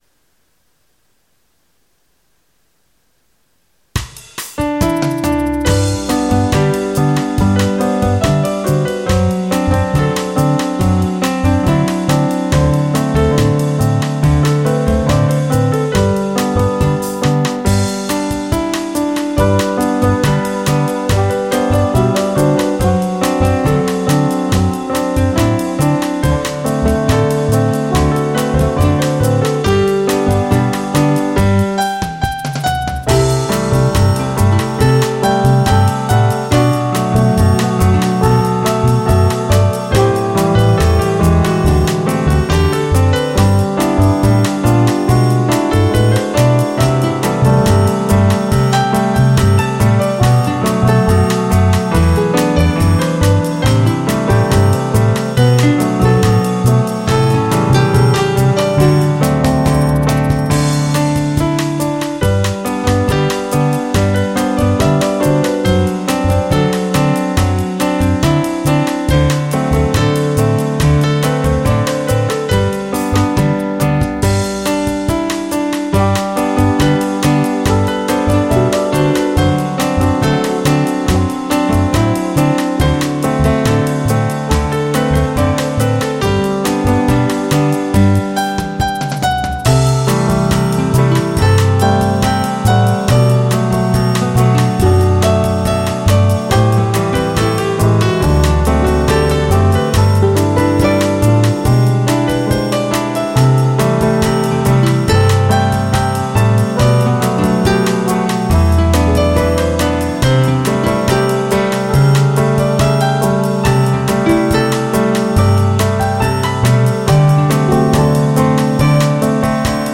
Happy Swing Piano & Band